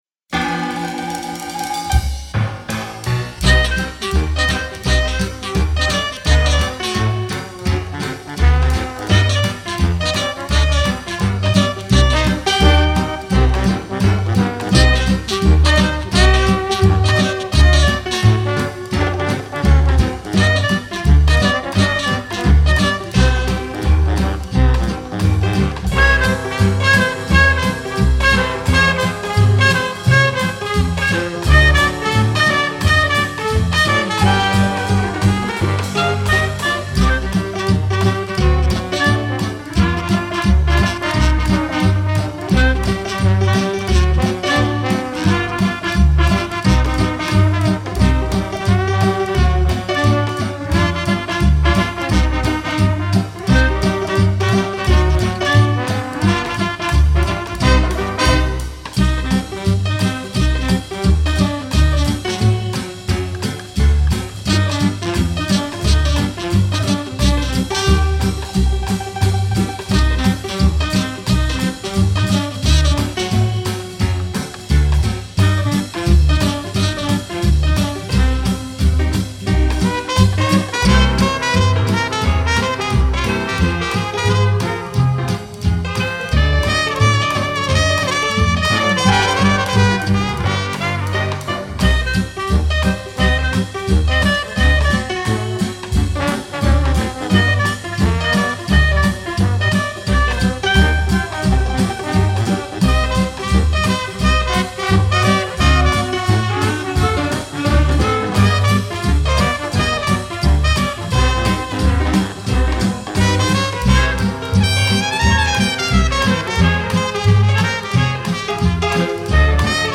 Правда, качество не очень.